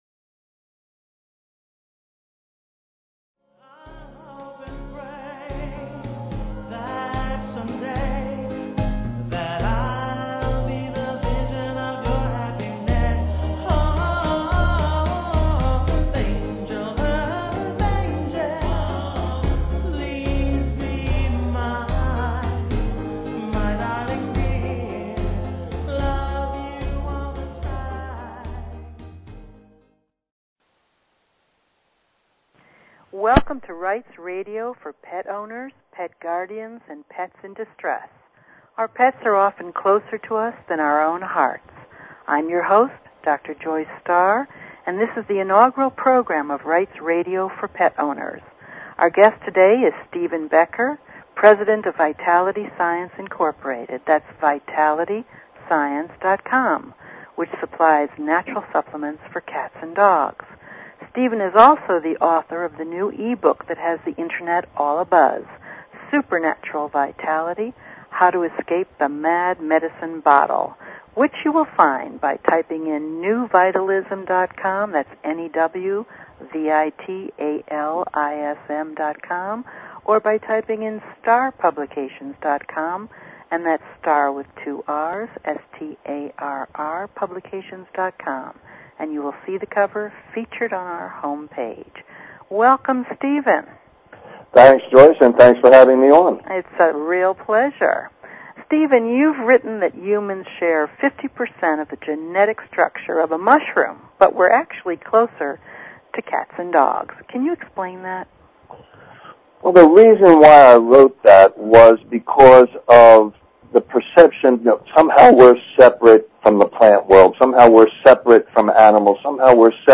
Talk Show Episode, Audio Podcast, Rights_Radio and Courtesy of BBS Radio on , show guests , about , categorized as